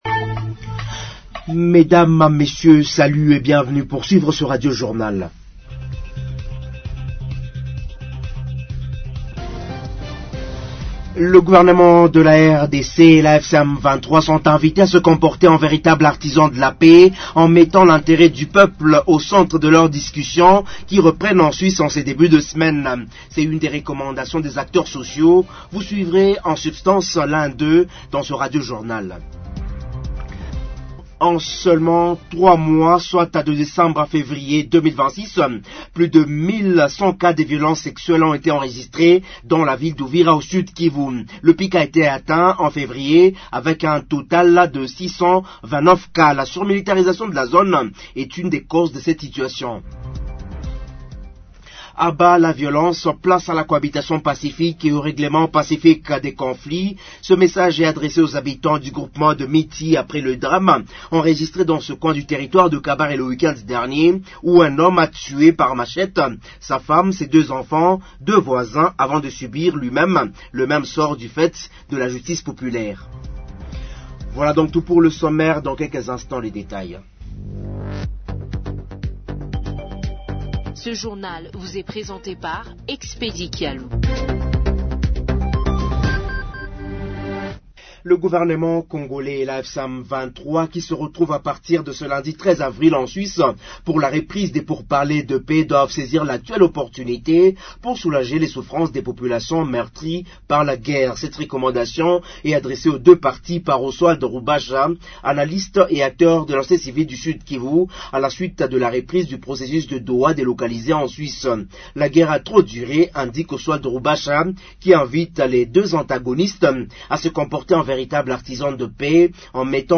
Journal en Français du 14 Avril 2026 – Radio Maendeleo